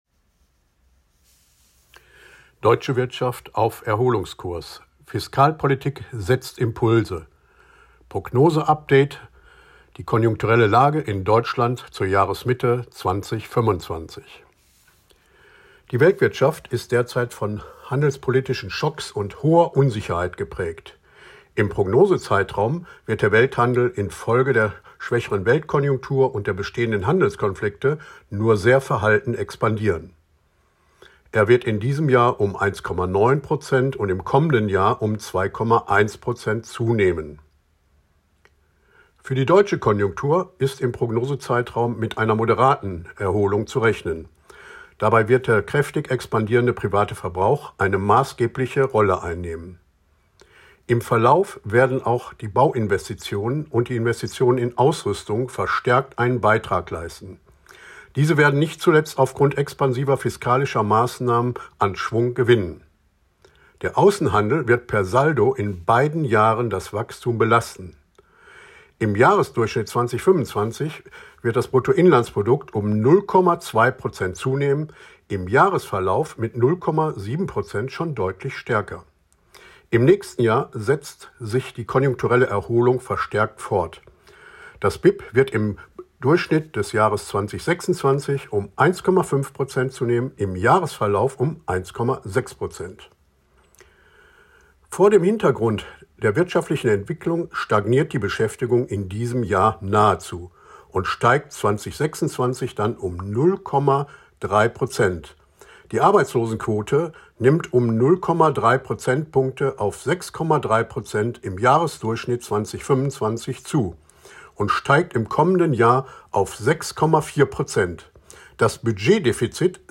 Kernergebnisse der Prognose im Audio-Statement